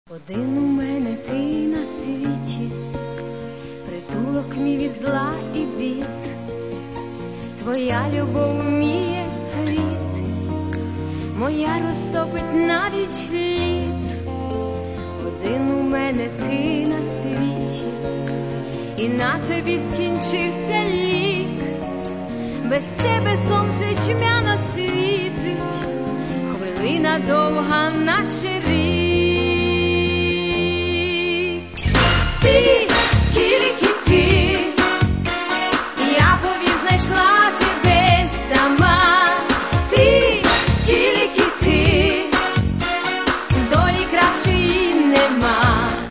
Каталог -> Естрада -> Співачки